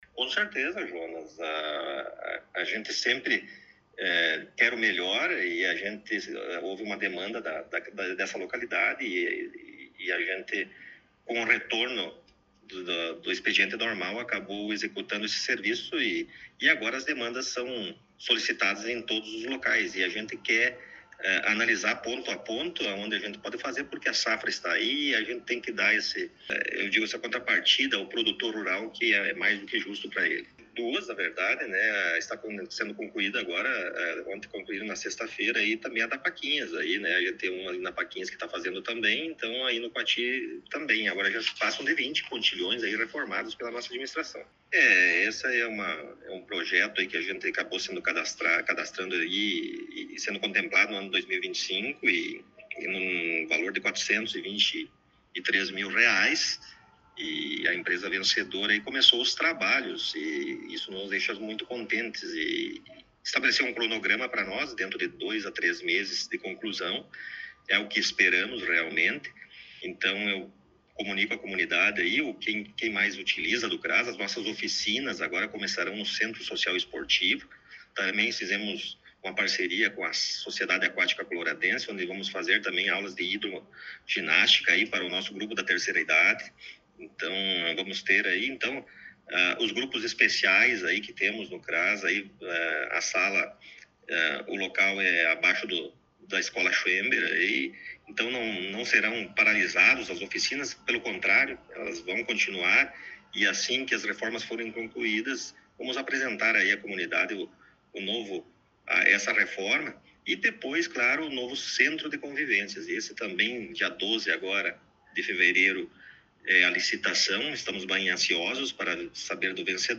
Entrevista com o Prefeito Rodrigo Sartori: IPTU 2026 e Obras no Município
Na última semana, em um encontro no gabinete da prefeitura, tivemos a oportunidade de entrevistar o prefeito Rodrigo Sartori, logo após seu retorno das férias.